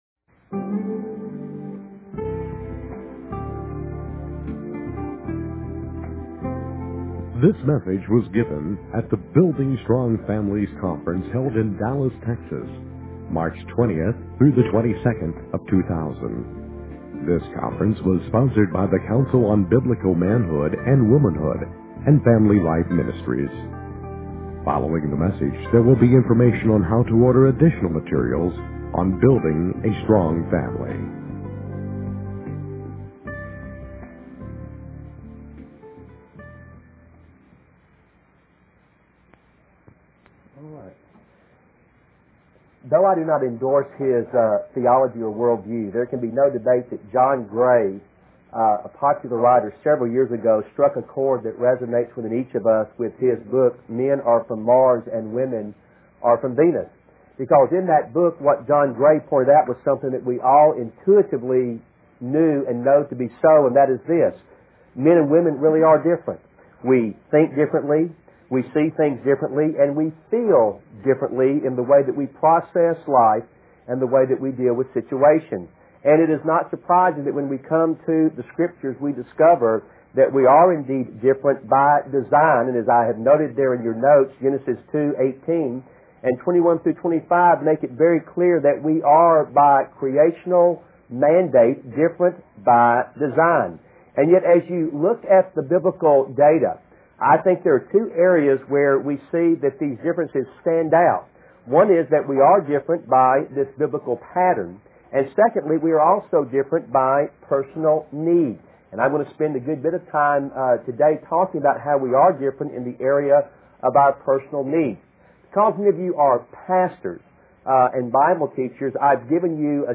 In this sermon, the preacher discusses how God has designed women and men differently. He emphasizes the importance of a woman's role as a nurturer and caregiver, highlighting the need for home support and stability. The preacher also talks about how men are often less expressive and more focused on action and competition.